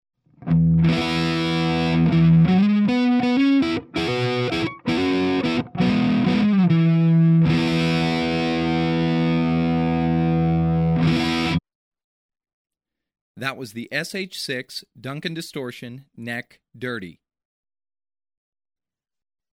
Duncan Distortion, SH-6n: halspositie, dirty sound Audio Unknown
sh_6_duncan_distortion_neck_dirty.mp3